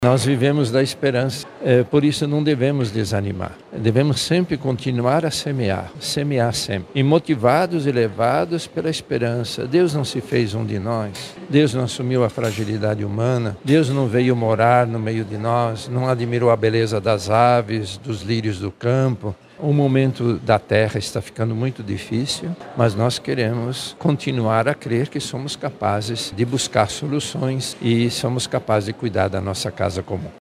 Na manhã desta quinta-feira, 27 de fevereiro, a Arquidiocese de Manaus realizou uma coletiva de imprensa para apresentar oficialmente a Campanha da Fraternidade 2025.
São Francisco de Assis, patrono da ecologia integral, é o santo inspirador desta CF. As palavras do Papa por meio da Lautado Si, a mensagem de esperança, o cuidado com o meio ambiente, devem ser um estímulo para boas práticas no cotidiano, como explica o Cardeal Leonardo Steiner.